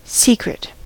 secret: Wikimedia Commons US English Pronunciations
En-us-secret.WAV